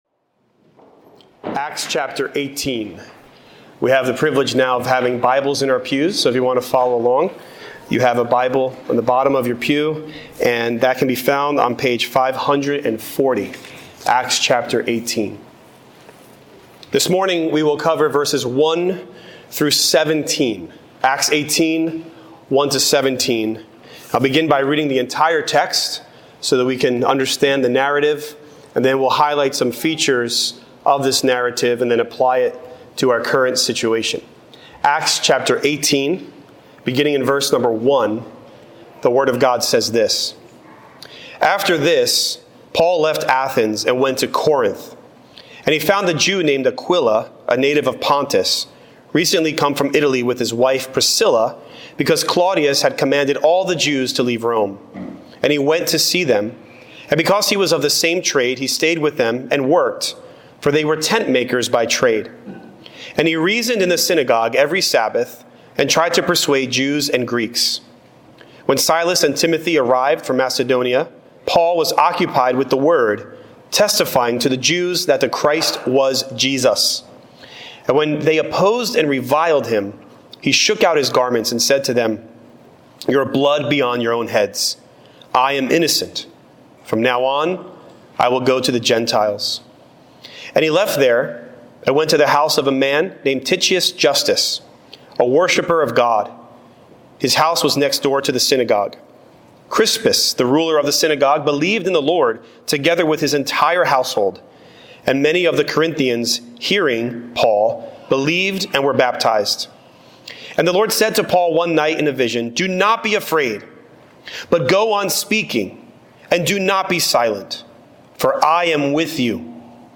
Many People In This City | SermonAudio Broadcaster is Live View the Live Stream Share this sermon Disabled by adblocker Copy URL Copied!